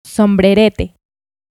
Pronunciation of the name of one of the towns classified as Pueblo Mágico